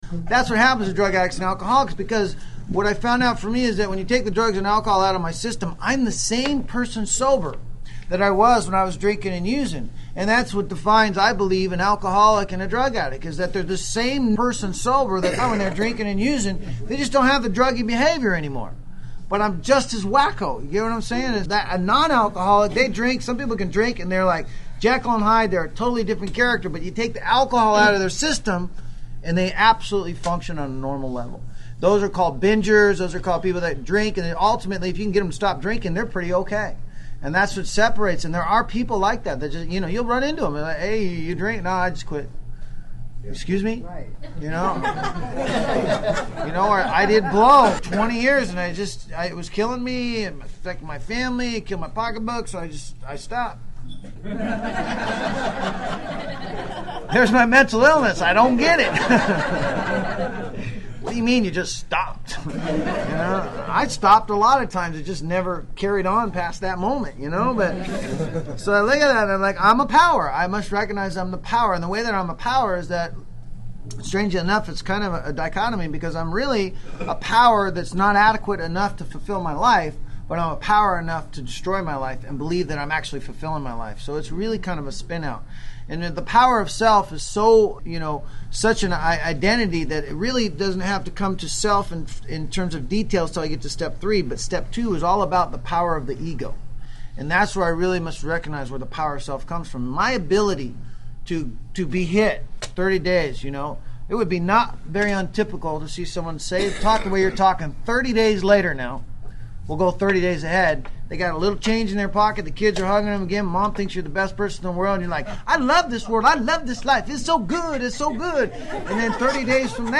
This audio archive is a compilation of many years of lecturing.